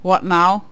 cudgel_select4.wav